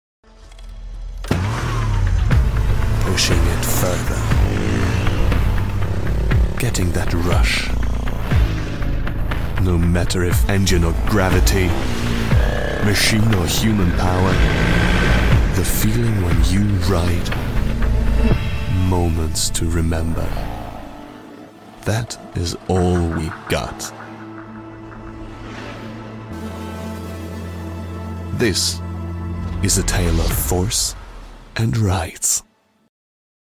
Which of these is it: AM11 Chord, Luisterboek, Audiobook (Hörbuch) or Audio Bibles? Audiobook (Hörbuch)